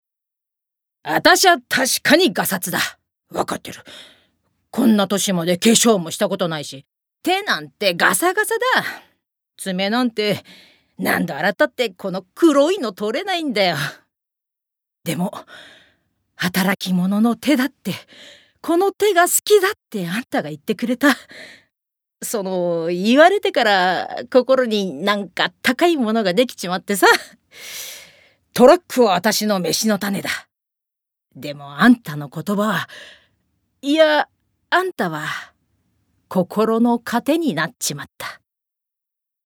ボイスサンプル
セリフ１